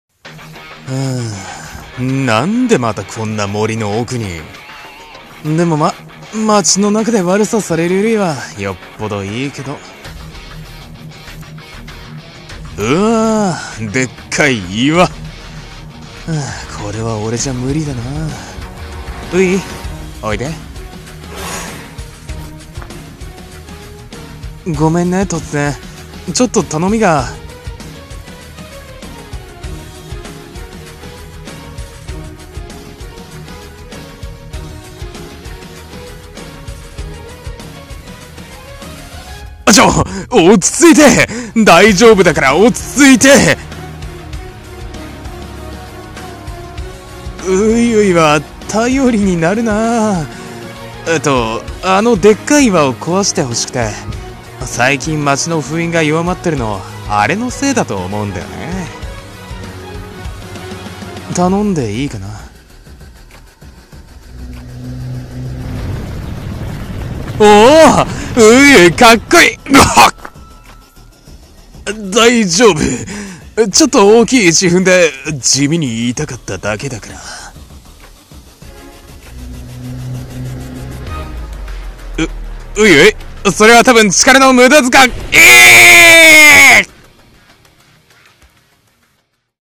【声劇台本】式神